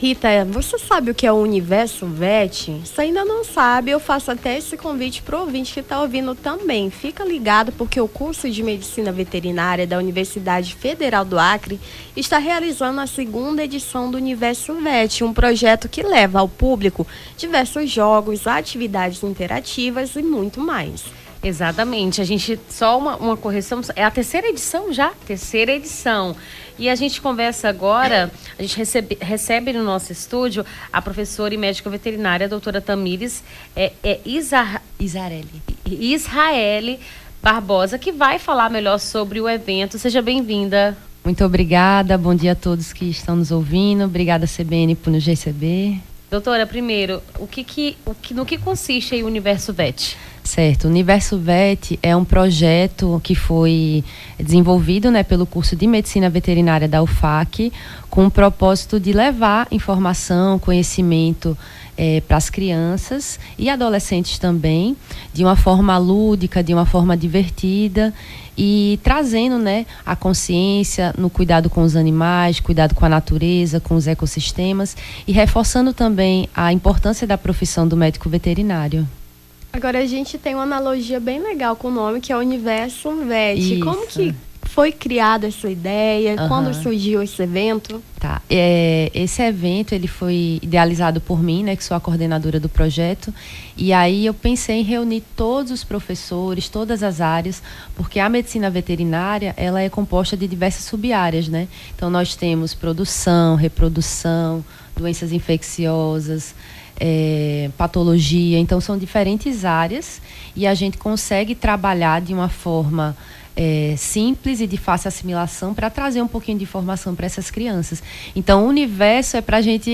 AO VIVO: Confira a Programação
Nome do Artista - CENSURA - ENTREVISTA UNIVERSO VET (13-12-24).mp3